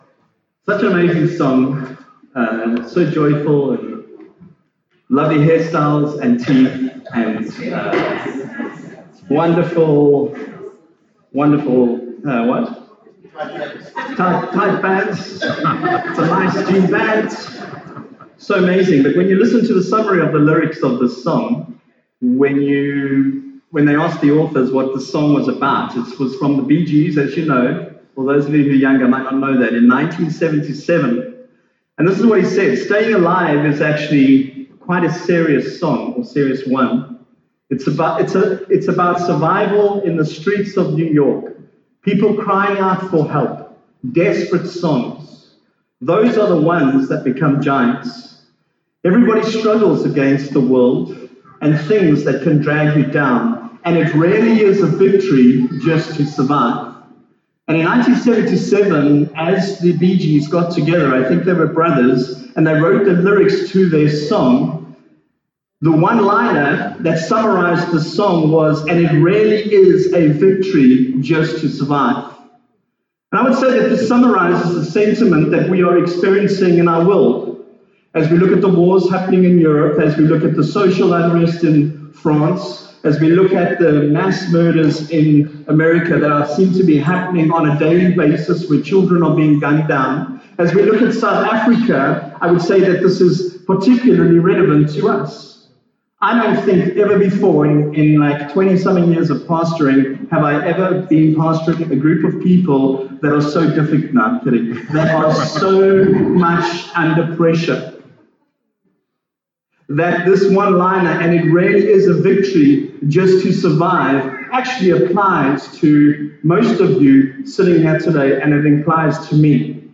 Sunday Service – 7 May